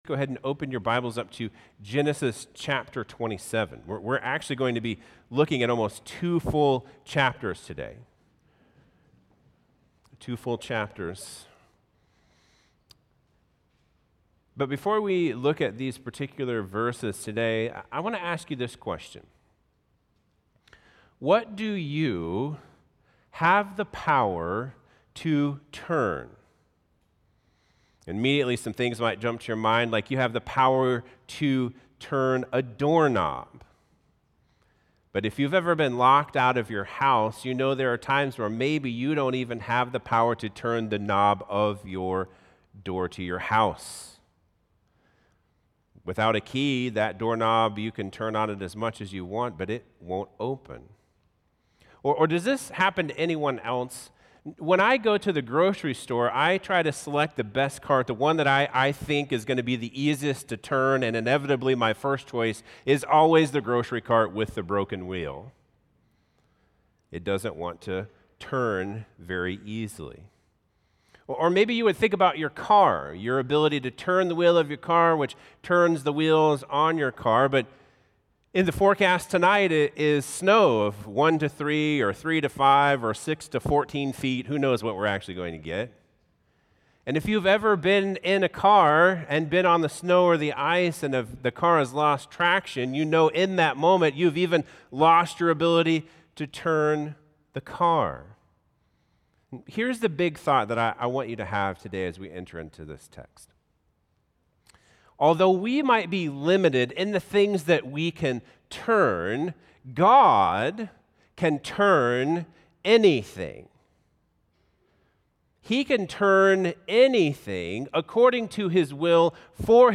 Sermons | CrossBridge Church